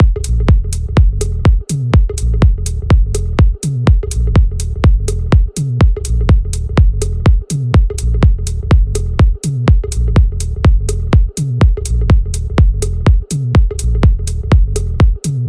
Techno Bass
Друзья, кто знает как накрутить такой плавающий бас?